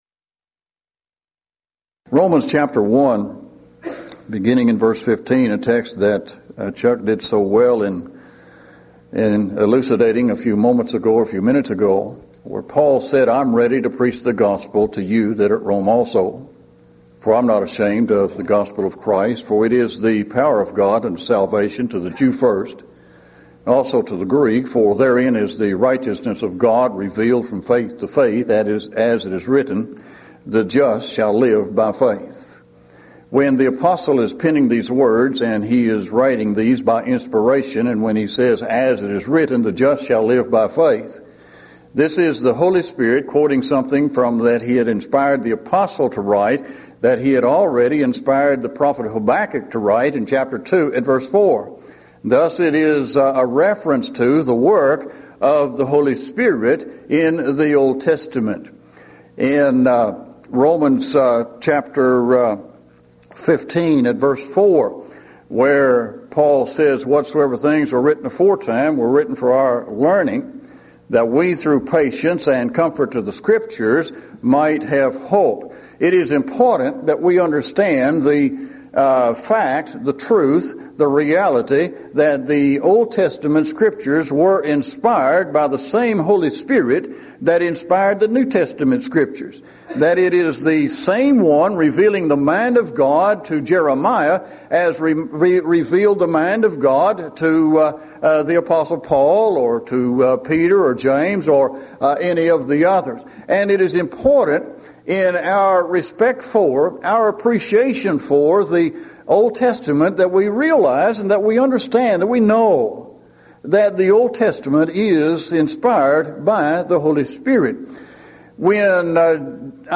Event: 1997 Mid-West Lectures
lecture